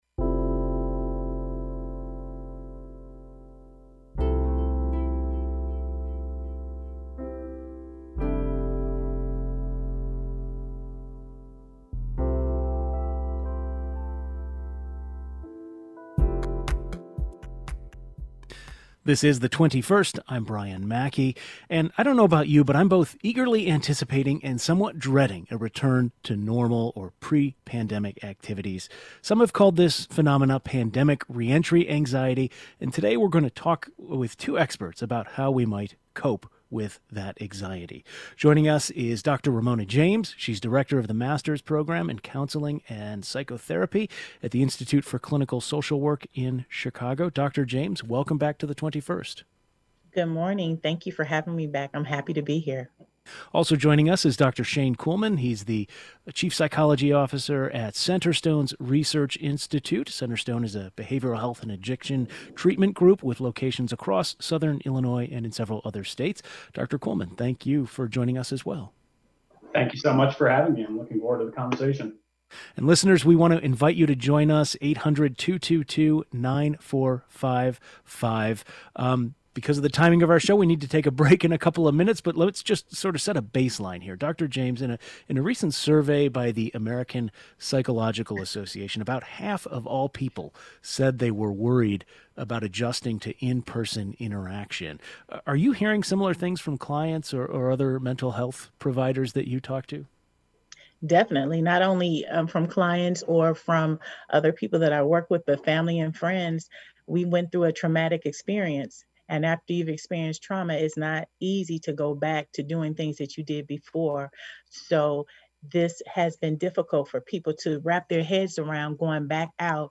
Two psychologists explained how we might cope with that anxiety.